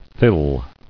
[thill]